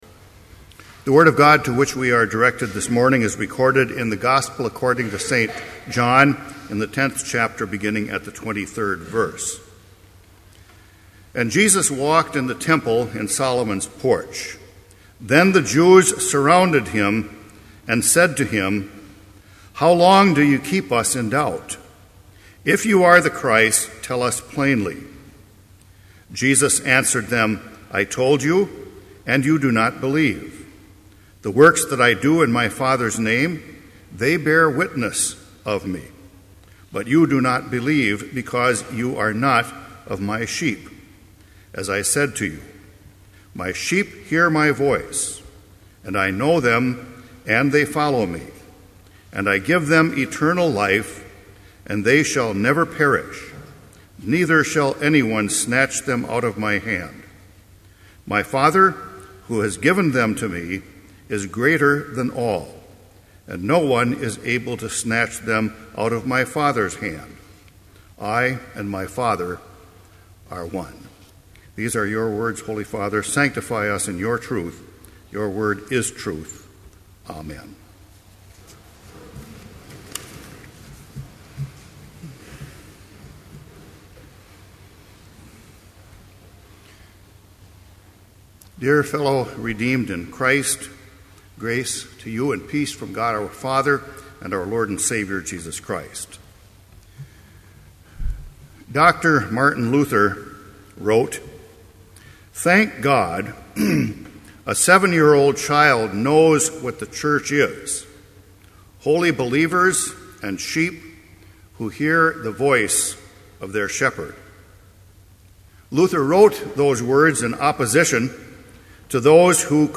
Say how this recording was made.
This Chapel Service was held in Trinity Chapel at Bethany Lutheran College on Thursday, April 26, 2012, at 10 a.m. Page and hymn numbers are from the Evangelical Lutheran Hymnary.